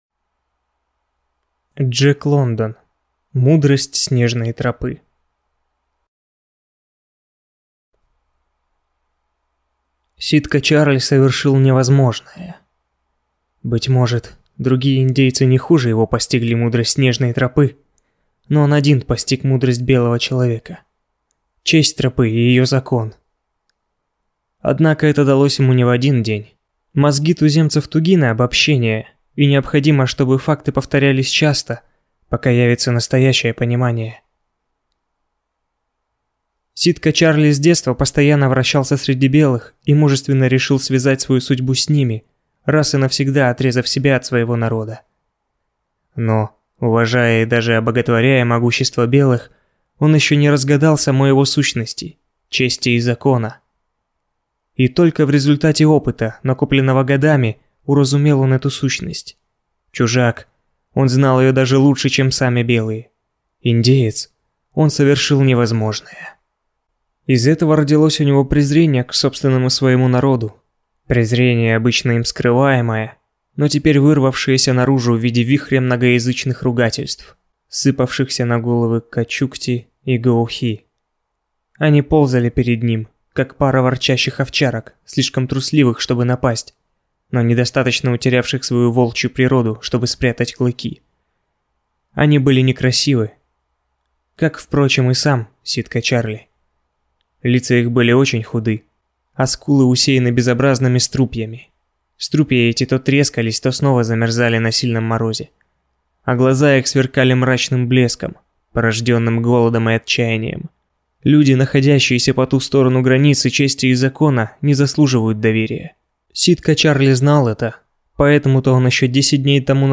Аудиокнига Мудрость снежной тропы | Библиотека аудиокниг